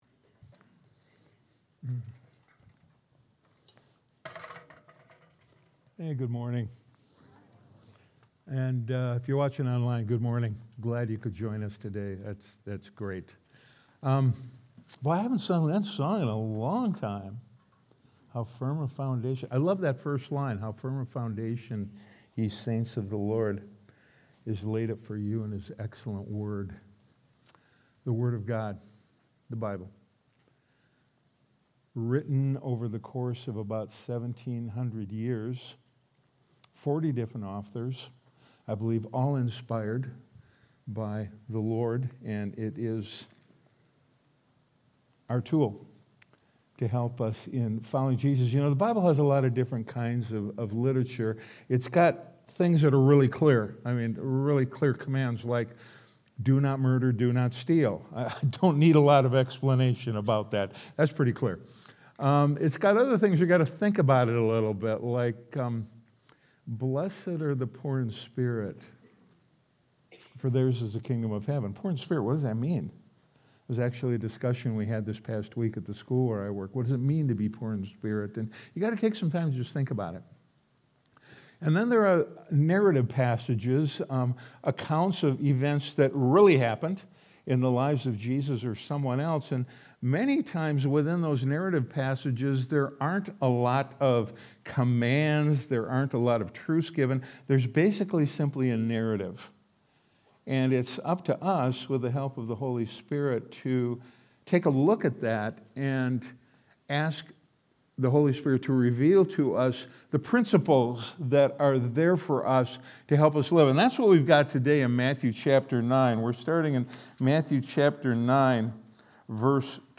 Sunday Sermon: 2-1-26